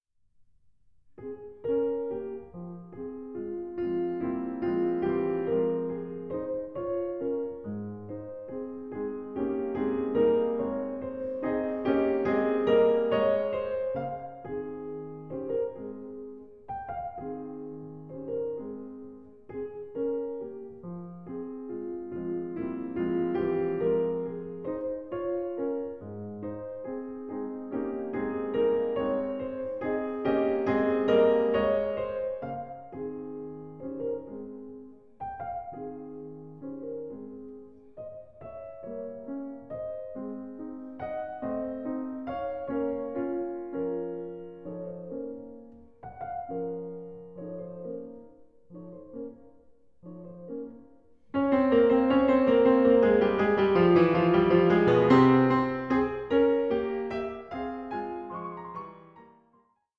Piano Sonata No. 1 in F minor, Op. 2, No. 1